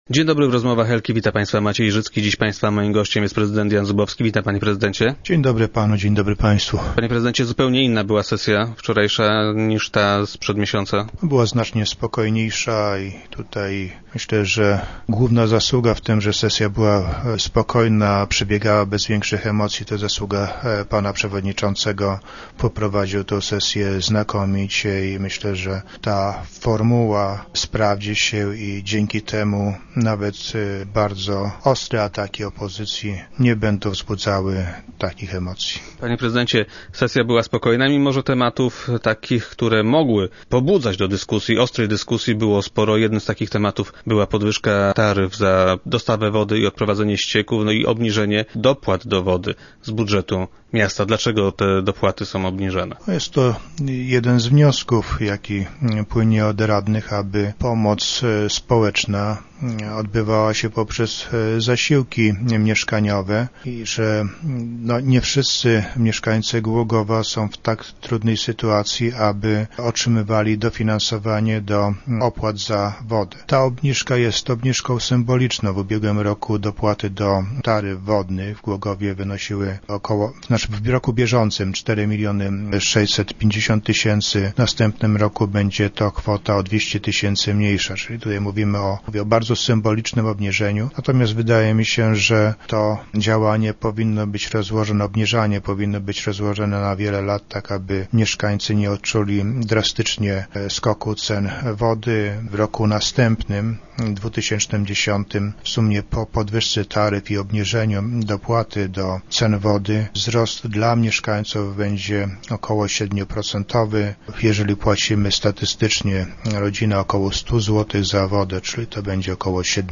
Tak w dzisiejszych Rozmowach Elki, decyzję o zmniejszeniu dopłat, tłumaczył prezydent Jan Zubowski.